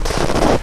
High Quality Footsteps
STEPS Snow, Walk 12.wav